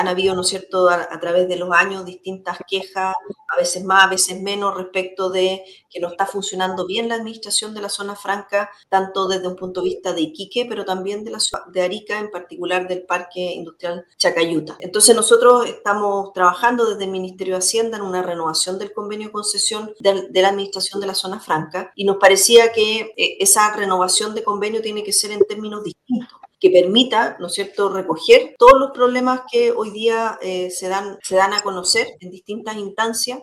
La subsecretaria de Hacienda, Heidi Berner, explicó en conversación con Radio Paulina que el Ministerio de Hacienda está trabajando en la renovación del convenio con ZOFRI S.A., pero con nuevas condiciones que permitan mayor transparencia en la administración y gestión de la zona franca.